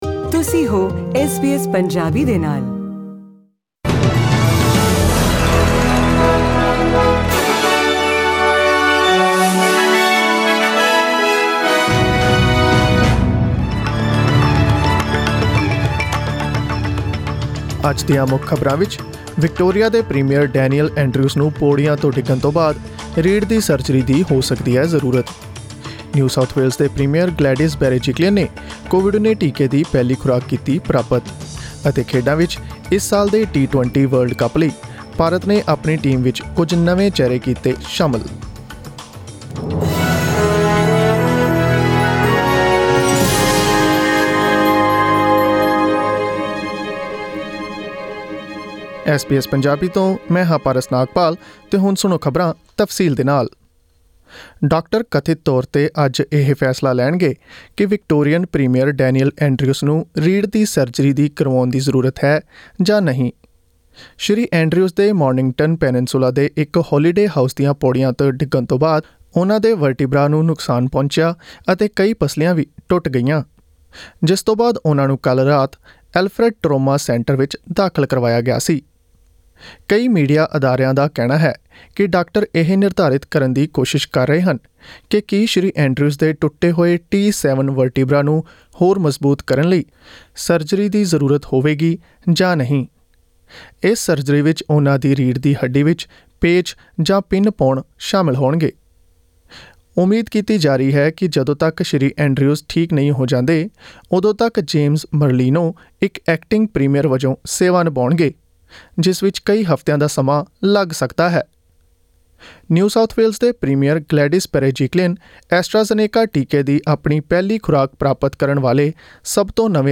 Australian News in Punjabi: Doctors will decide whether Premier Daniel Andrews needs to have spinal surgery
Click the audio icon on the photo above to listen to the full news bulletin in Punjabi